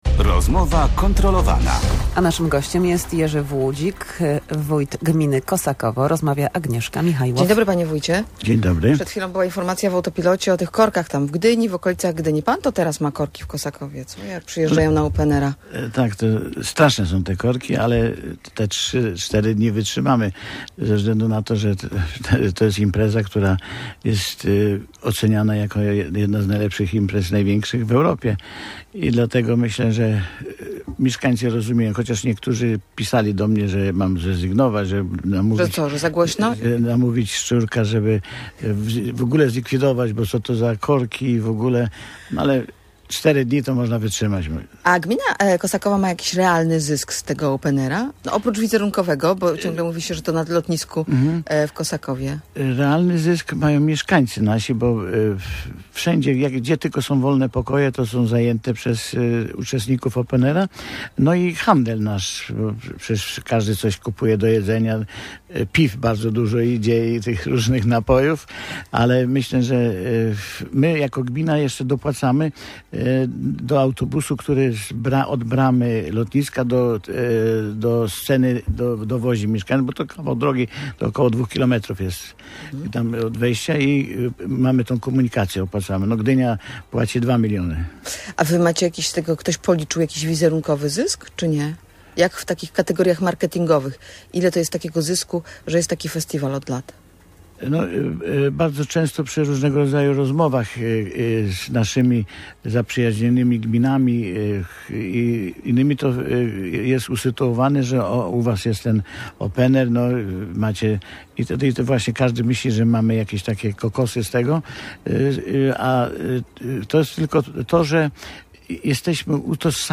O tym i o innych problemach gminy opowiadał jej wójt Jerzy Włudzik, który był gościem Rozmowy Kontrolowanej w Radiu Gdańsk.